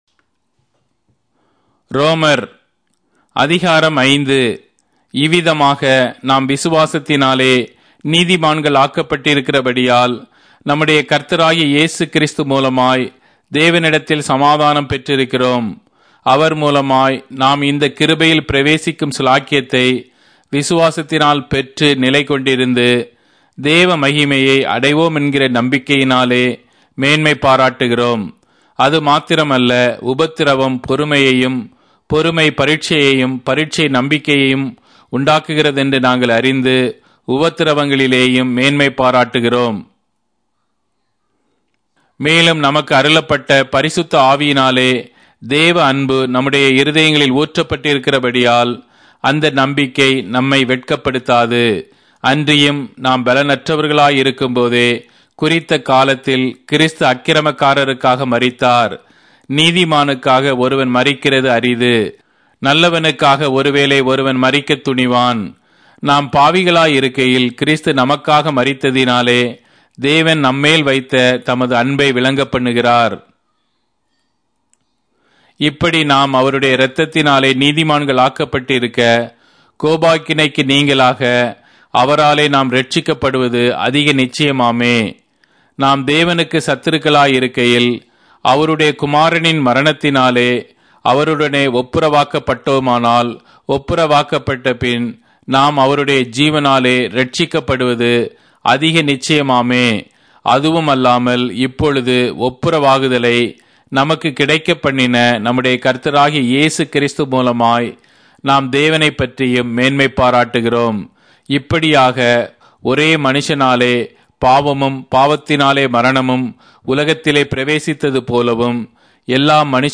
Tamil Audio Bible - Romans 10 in Ylt bible version